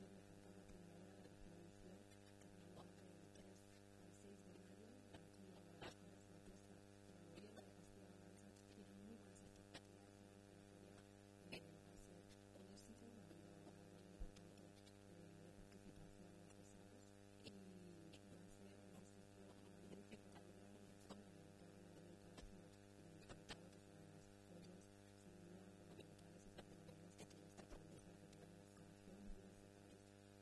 La directora general de Turismo, Comercio y Artesanía, Ana Isabel Fernández Samper, habla de la celebración en Guadalajara de FARCAMA Primavera.